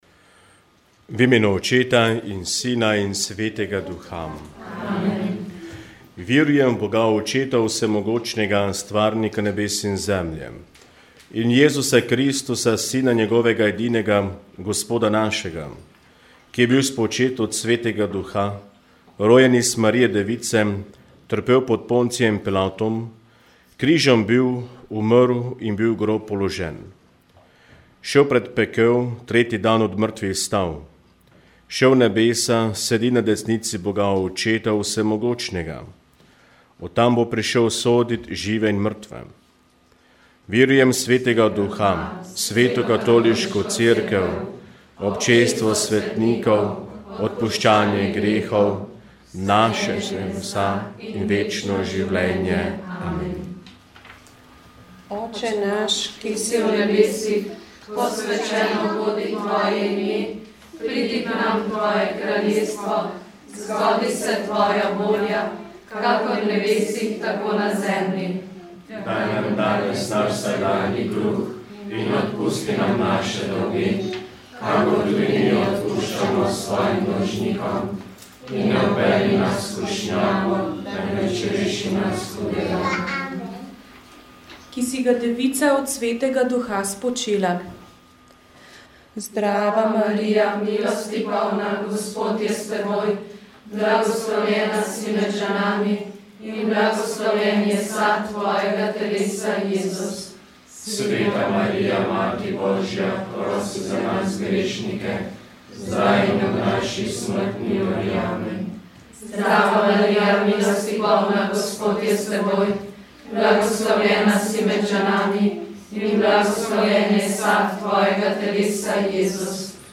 V pogovoru, je predstavil akcijo, ki je v teku in letos praznuje trideset let.